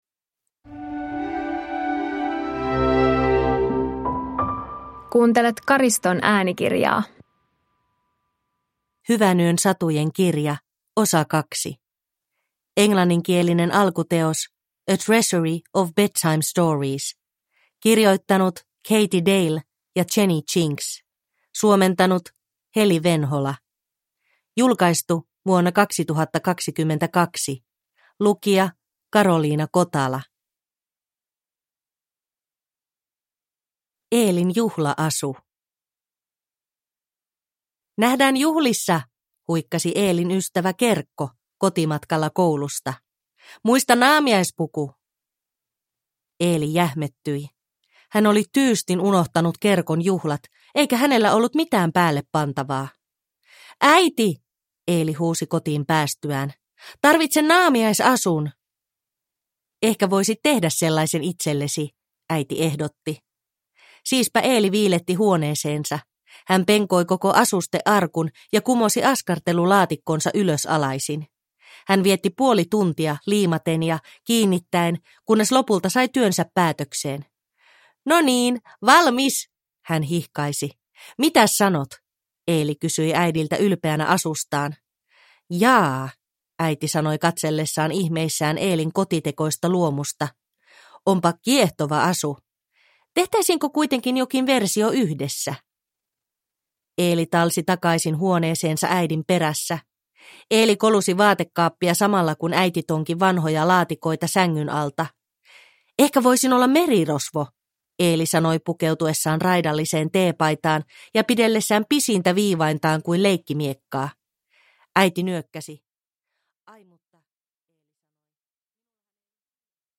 Hyvänyön satujen kirja 2 – Ljudbok – Laddas ner